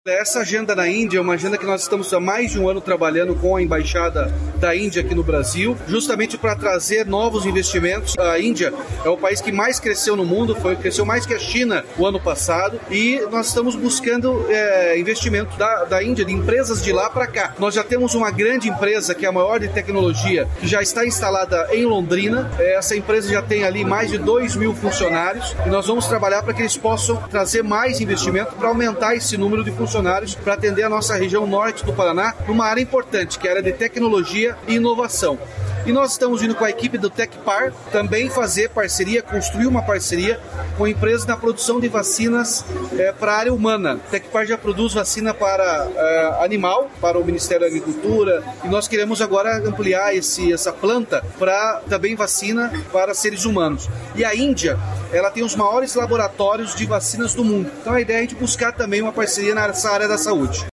Sonora do governador Ratinho Junior sobre a missão internacional para a Índia